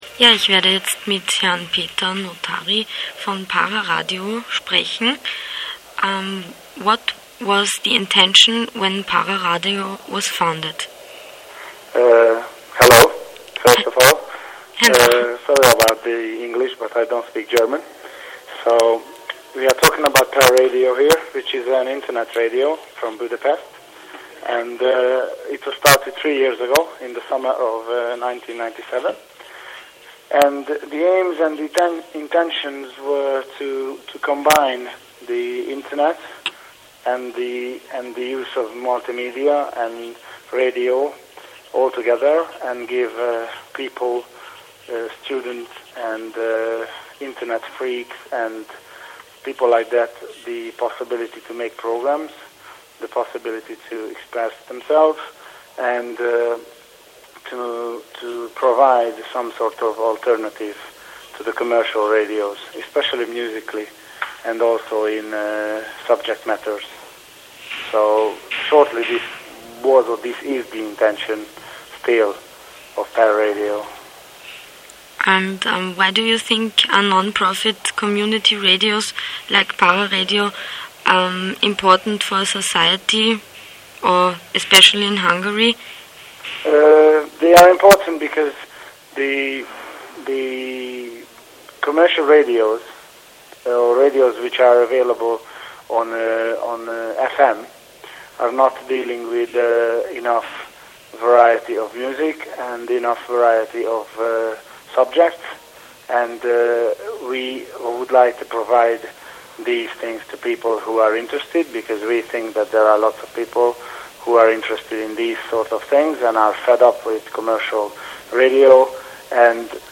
Interview mit Pararadio3,8 MB